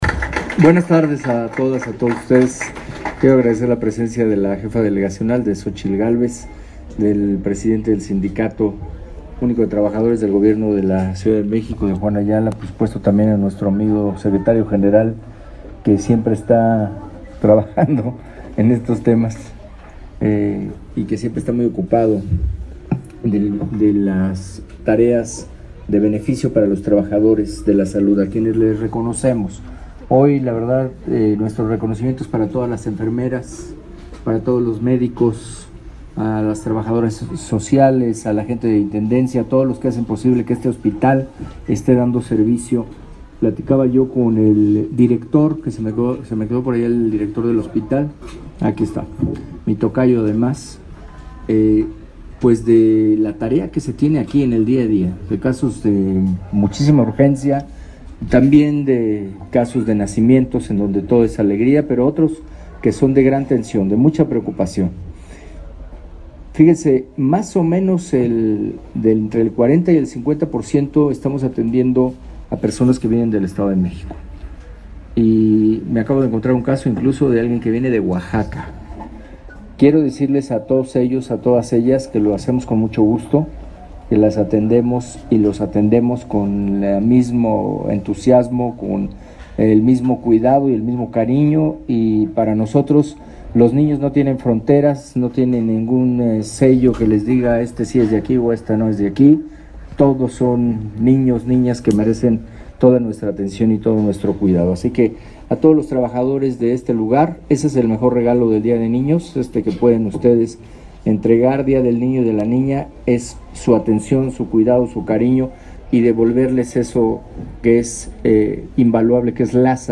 El personal médico del Hospital Pediátrico de Legaria atiende a pacientes de la capital del país y de otras entidades, aseguró el Jefe de Gobierno de la Ciudad de México, Miguel Ángel Mancera Espinosa, al acudir al nosocomio ubicado en la delegación Miguel Hidalgo para convivir con padres de familia y menores.